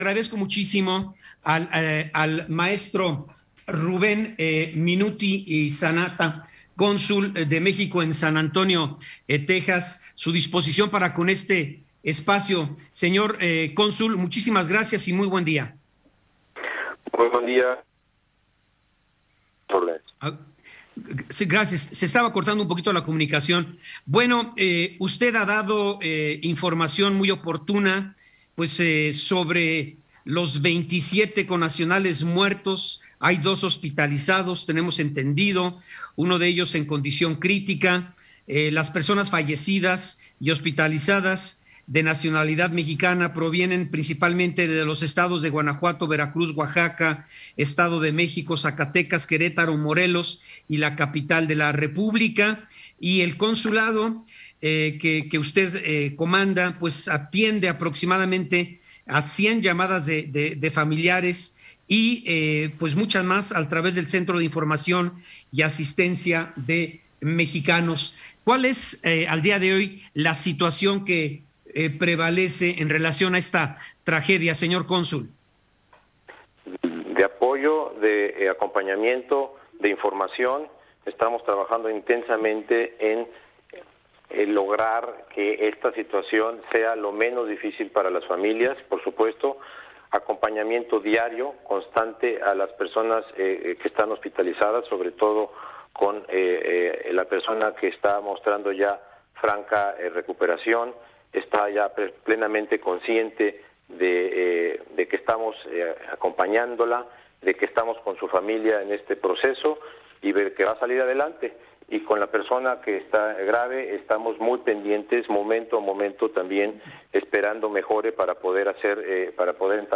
Ciro Gómez Leyva habla sobre la estrategia de seguridad de AMLO - 23.06.2022